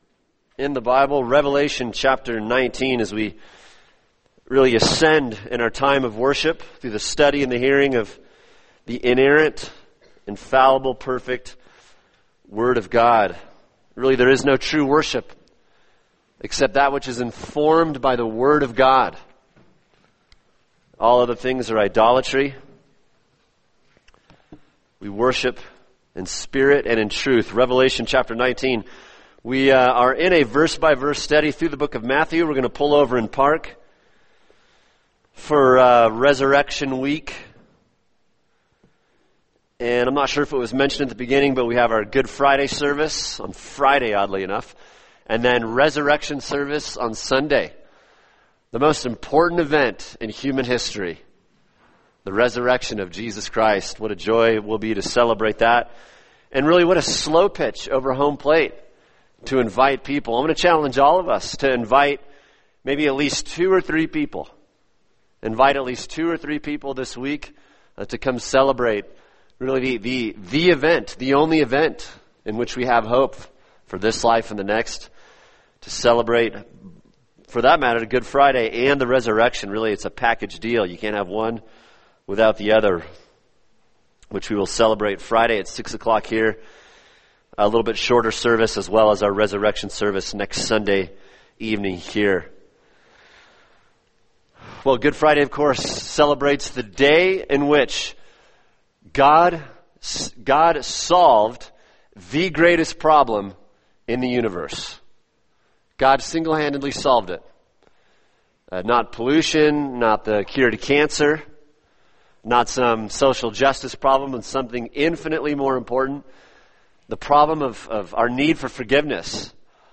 [sermon] Revelation 19:11-21 The Return Of Christ | Cornerstone Church - Jackson Hole